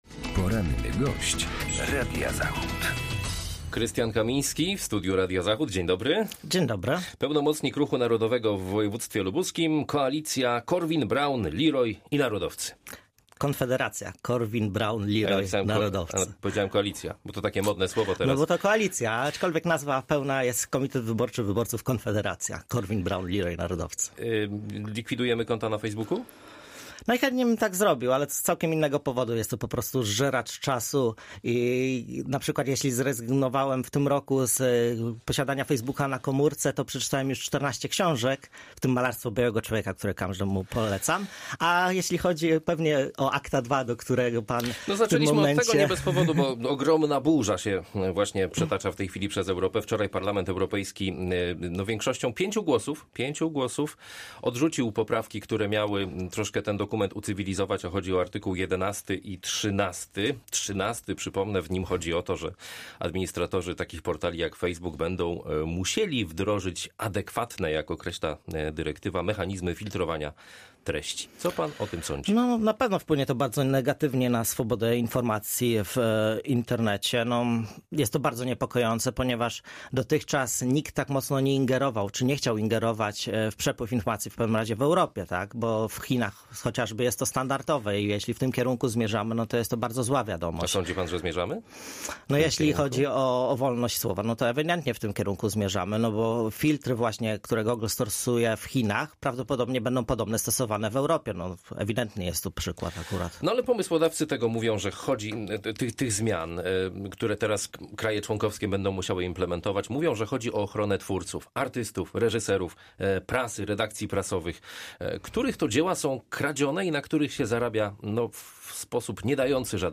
Z lubuskim pełnomocnikiem Ruchu Narodowego rozmawia